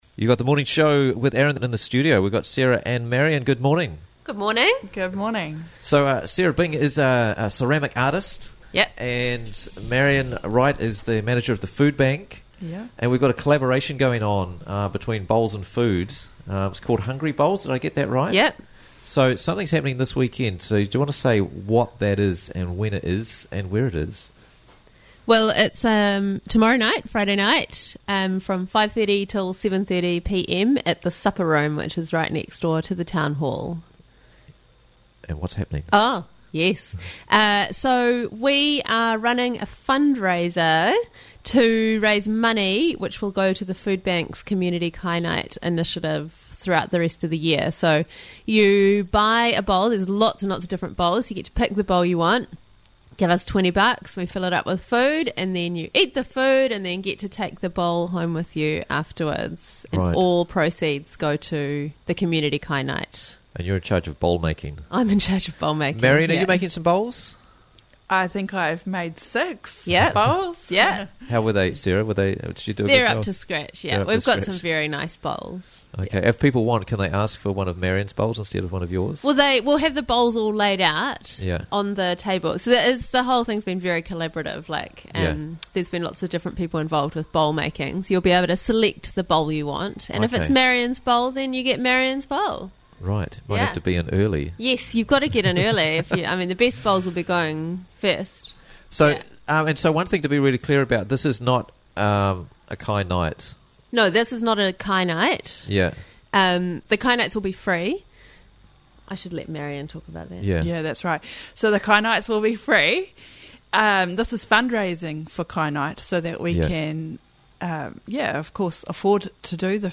Hungry Bowls Fundraiser Friday - Interviews from the Raglan Morning Show